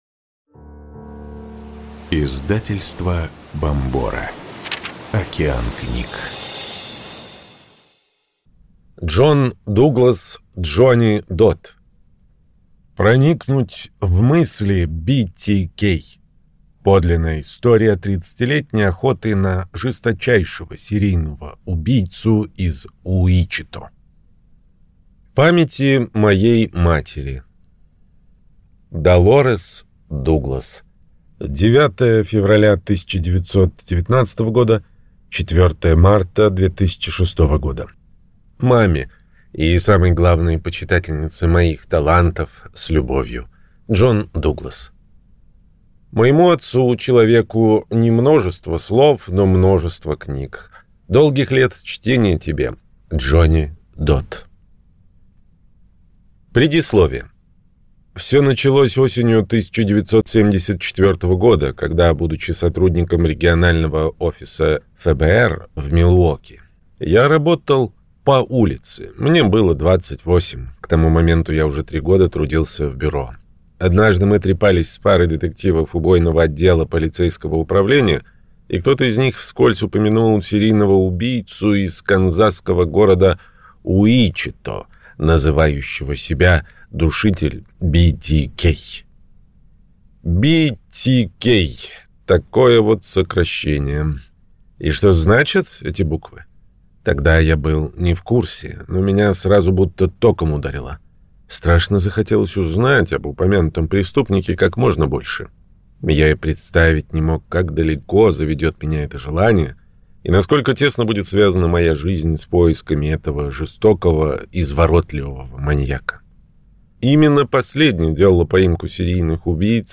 Аудиокнига Проникнуть в мысли BTK. Подлинная история тридцатилетней охоты на жесточайшего серийного убийцу из Уичито | Библиотека аудиокниг